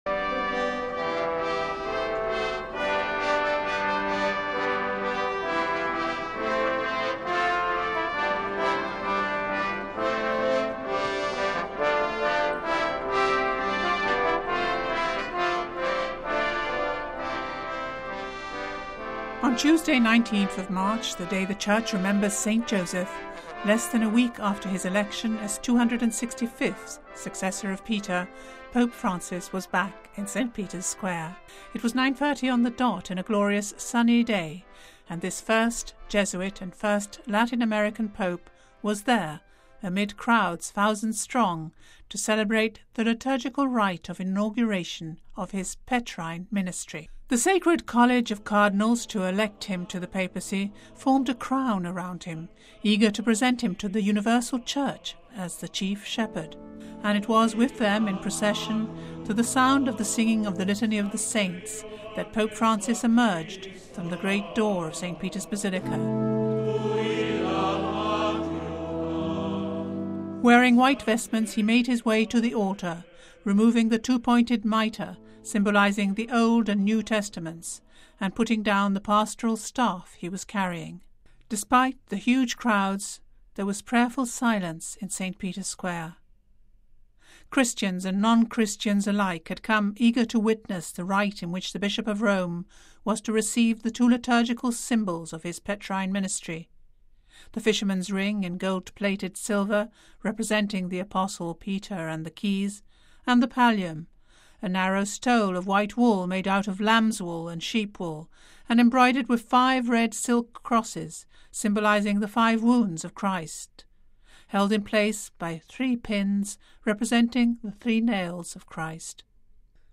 Pope Francis: Holy Mass for inauguration of Petrine Ministry
It was 9.30 on the dot and a glorious sunny day and this first Jesuit and first Latin American Pope was there amid crowds, thousand strong, to celebrate the liturgical rite of inauguration of his Petrine Ministry.
And it with them in procession – to the sound of the singing of the Litany of the Saints that Pope Francis emerged from the great door of Saint Peter’s Basilica.
Despite the crowds there was prayerful silence in Saint Peter’s Square Christians and non-Christians alike had come eager to witness the rite in which the Bishop of Rome was to receive the two liturgical symbols of his Petrine Ministry: the fisherman’s ring in gold-plated silver representing the Apostle Peter and the keys .